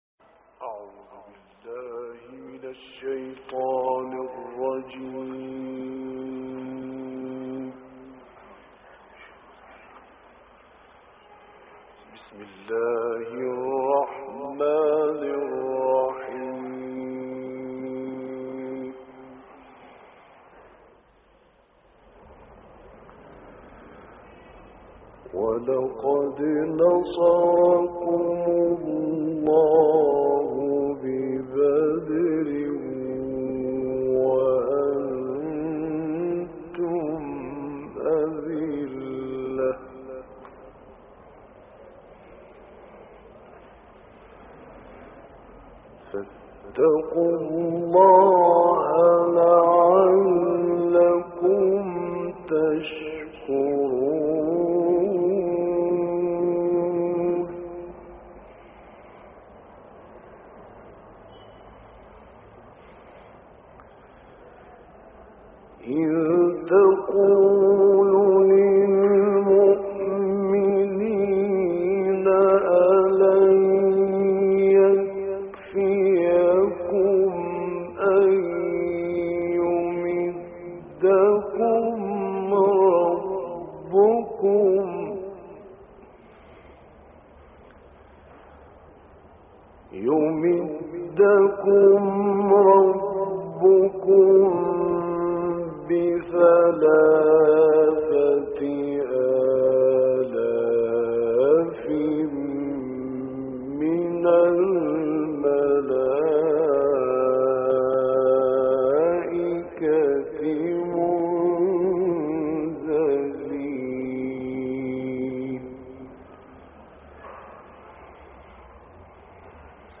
دانلود قرائت سوره آل عمران آیات 123 تا 144 - استاد راغب مصطفی غلوش